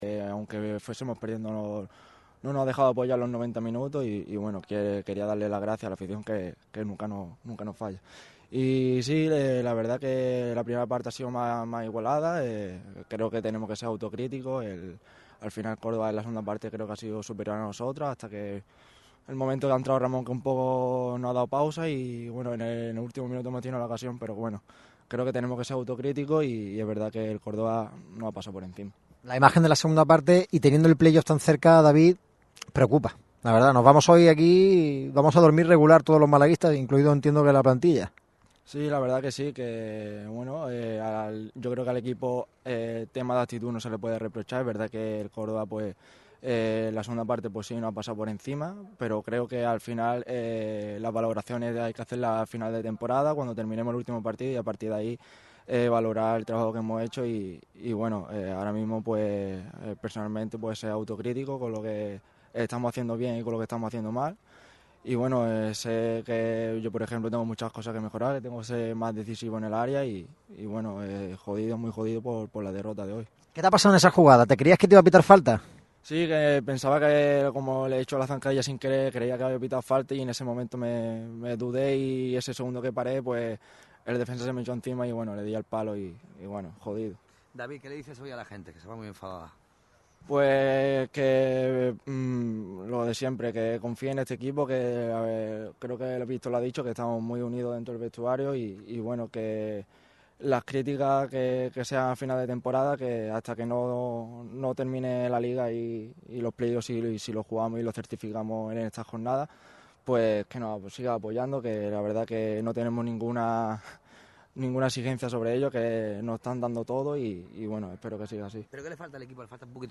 El jugador del Málaga CF, David Larrubia, ha comparecido ante los medios en zona mixta después de la derrota en el Nuevo Arcángel. El del barrio de la Luz ha hecho autocrítica sobre el partido del equipo y ha reconocido la más que evidente falta de gol: «No estoy siendo decisivo».